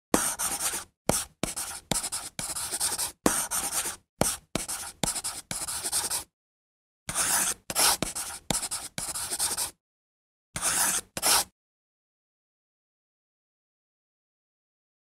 KGmcf2RhRKh_Efecto-Escritura-Pizarra.mp3